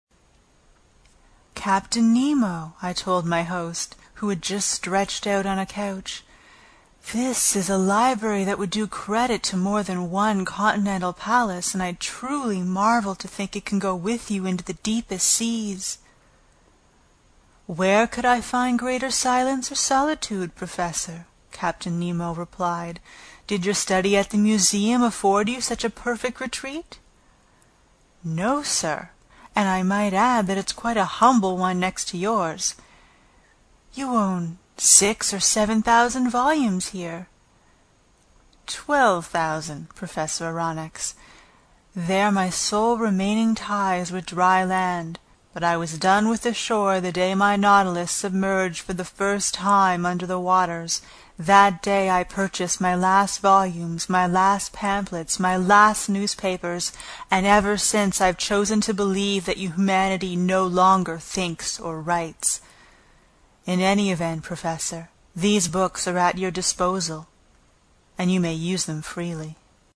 英语听书《海底两万里》第151期 第11章 诺第留斯号(2) 听力文件下载—在线英语听力室
在线英语听力室英语听书《海底两万里》第151期 第11章 诺第留斯号(2)的听力文件下载,《海底两万里》中英双语有声读物附MP3下载